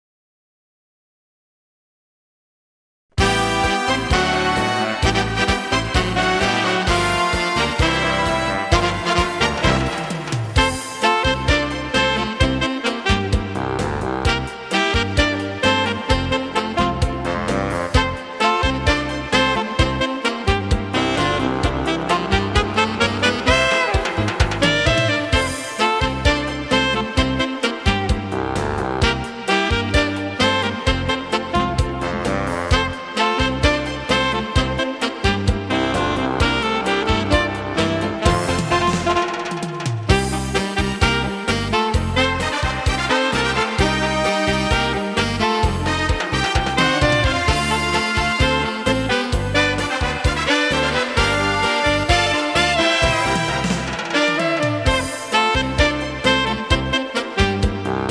Cha cha cha
12 brani per sax e orchestra.